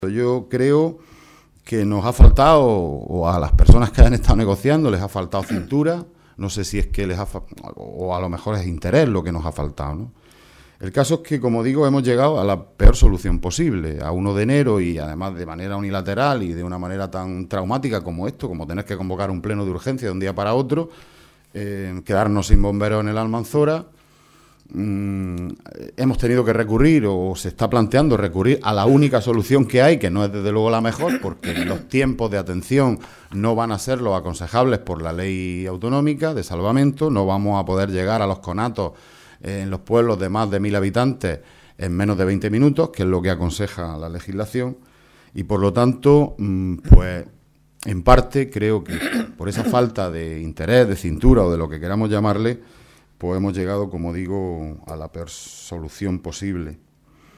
Juan Antonio Lorenzo, portavoz del Grupo Socialista en la Diputación Provincial